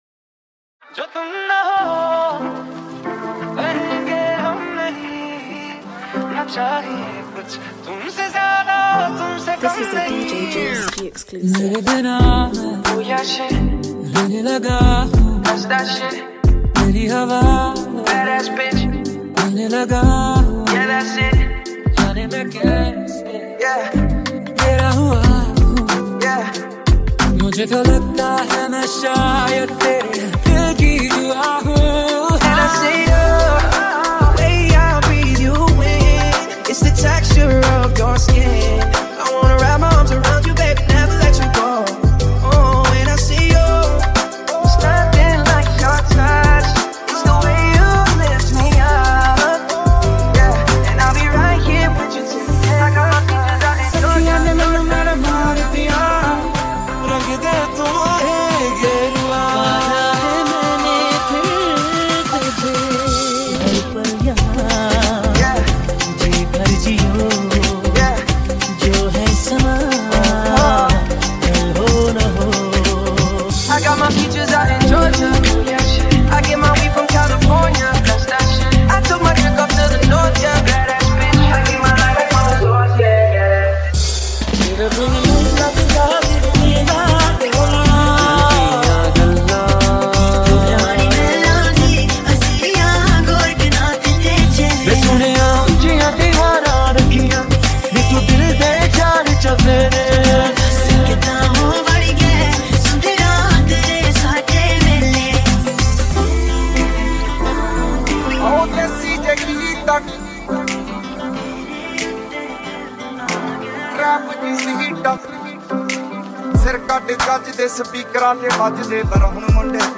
Romantic Mashup